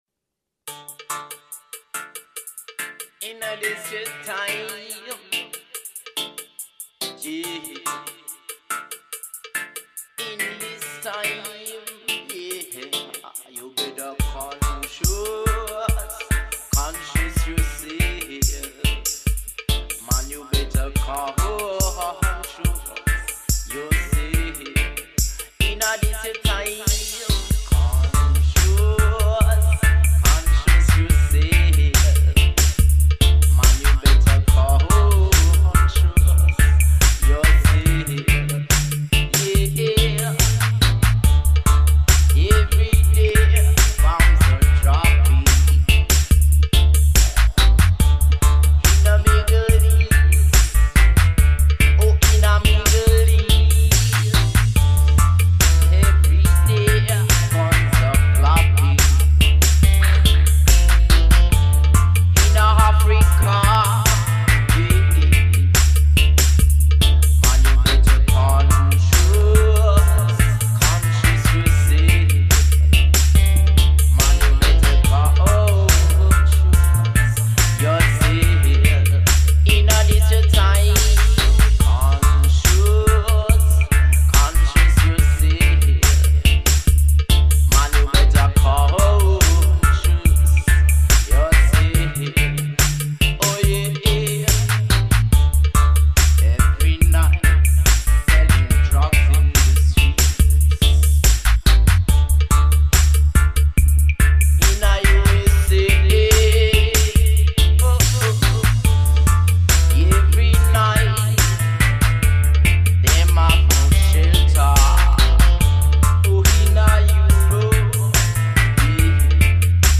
positive reggae vibes